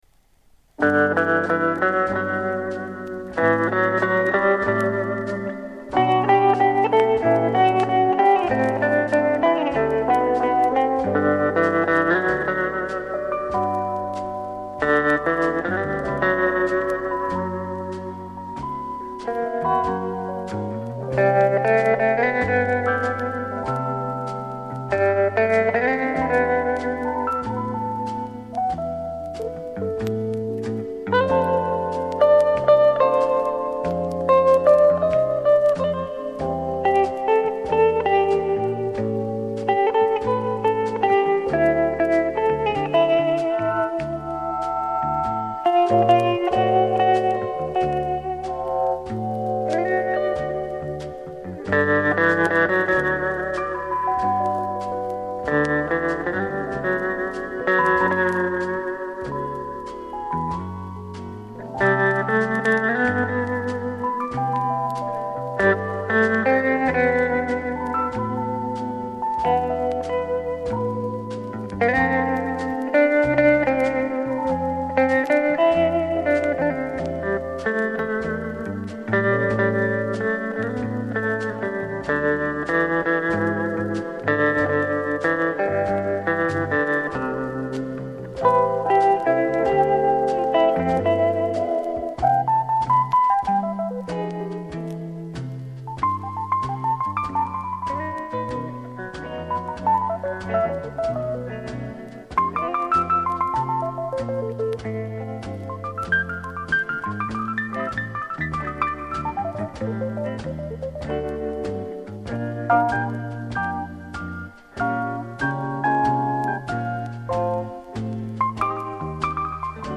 Известный гитарист.